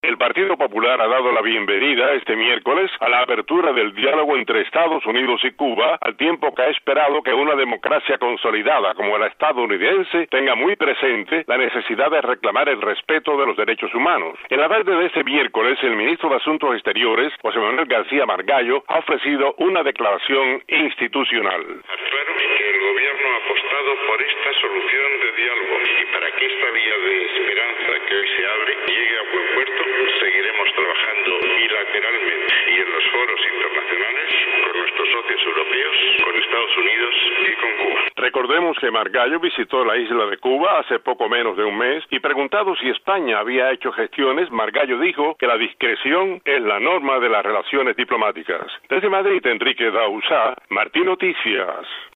El canciller español José Manuel García Margallo afirmó que España seguirá trabajando en los foros internacionales por que “llegue a buen puerto” los lazos abiertos entre Estados Unidos y Cuba. Detalles desde Madrid